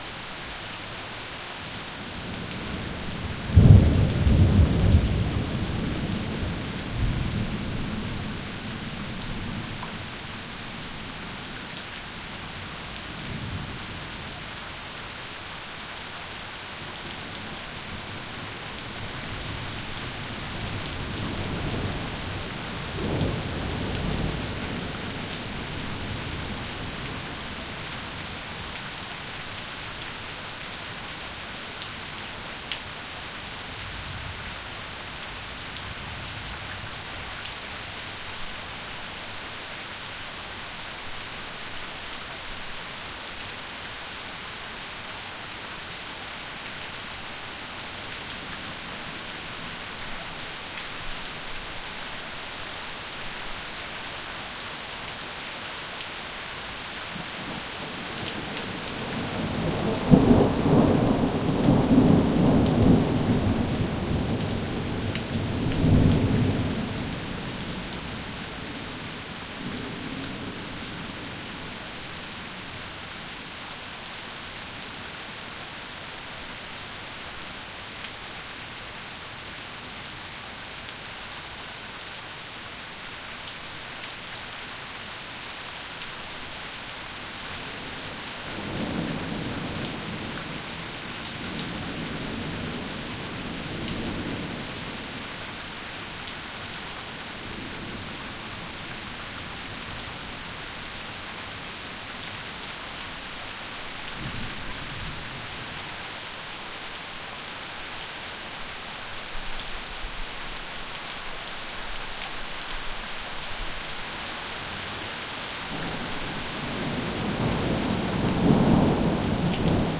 rainLoop.ogg